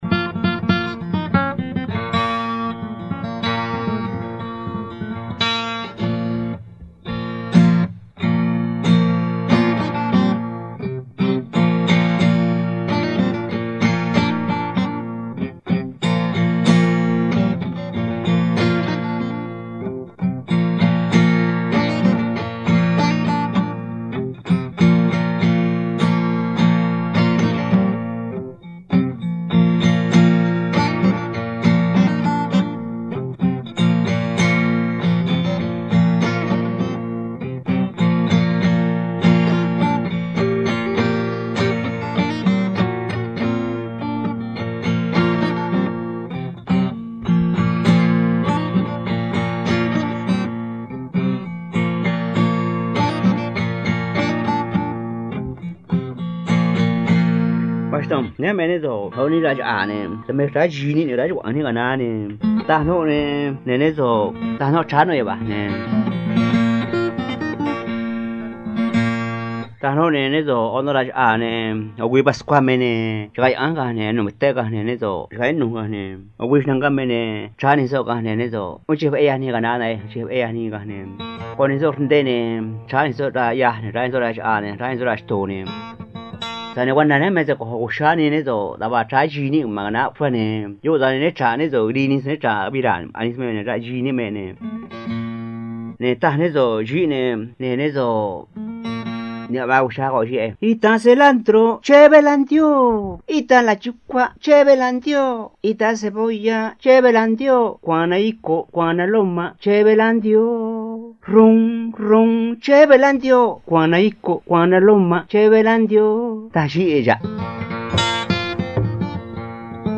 Música Tradicional Triqui